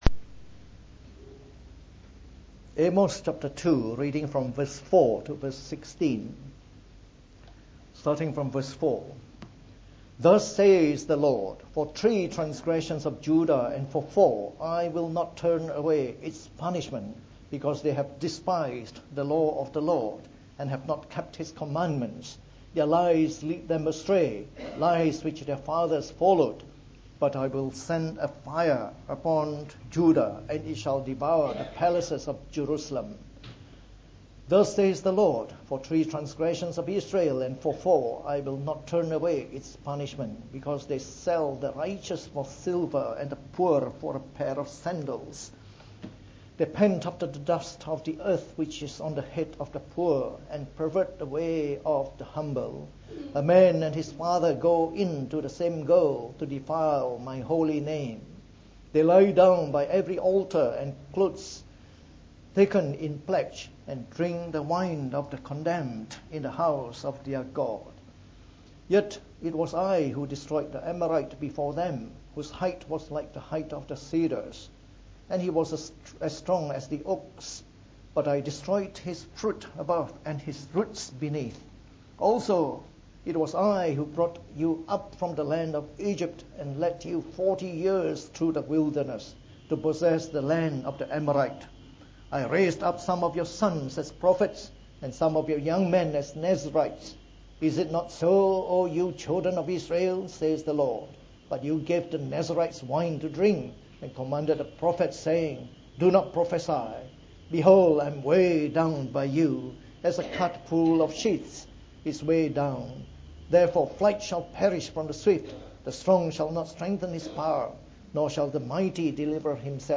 From our series on the Book of Amos delivered in the Morning Service.